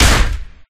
Blow10.ogg